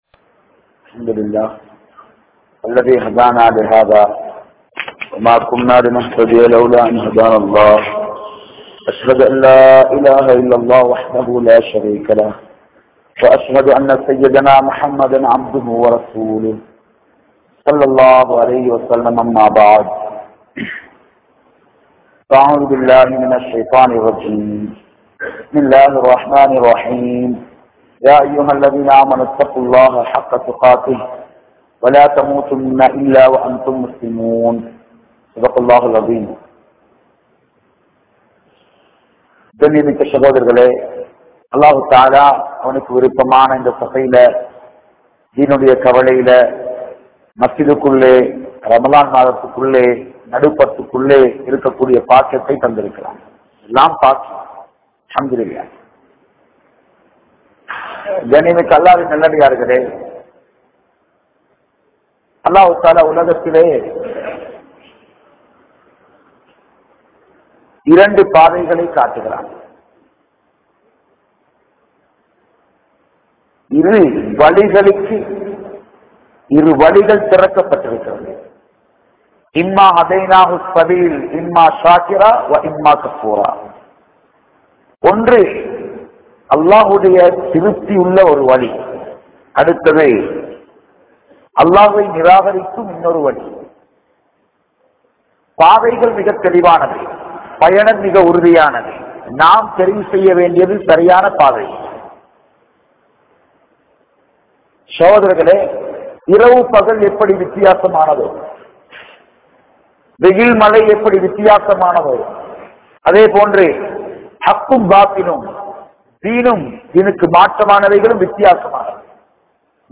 Allah`vin Thodarfai Thundiththavarhal (அல்லாஹ்வின் தொடர்பை துண்டித்தவர்கள்) | Audio Bayans | All Ceylon Muslim Youth Community | Addalaichenai